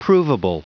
Prononciation du mot provable en anglais (fichier audio)
Prononciation du mot : provable